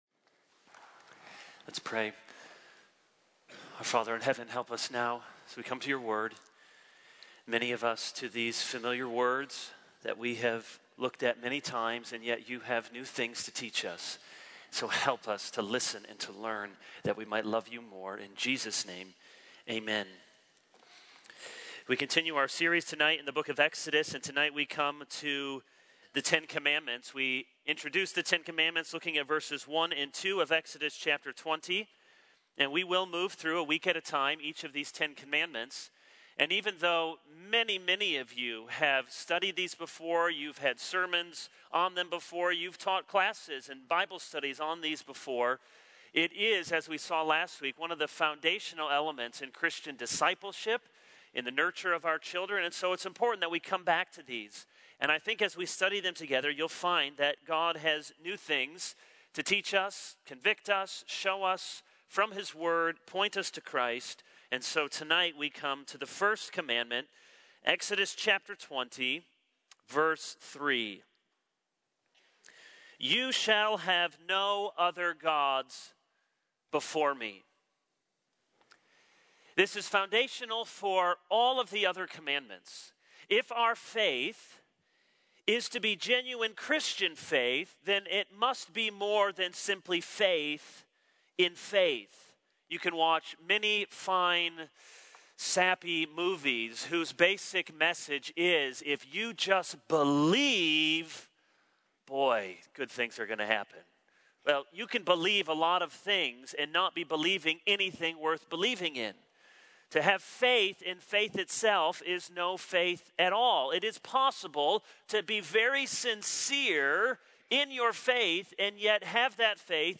This is a sermon on Exodus 20:3.